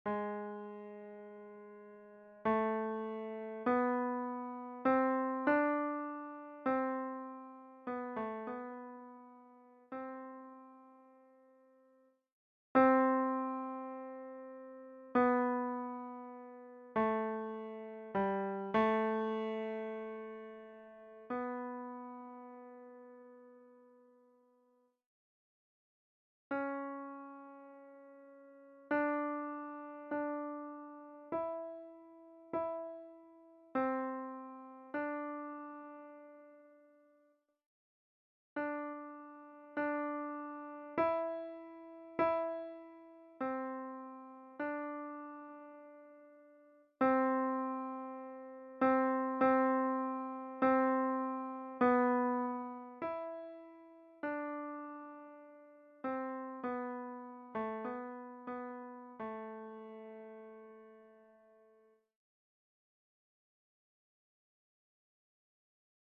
Voix : SATB
Fonction : Communion
MP3 Tenor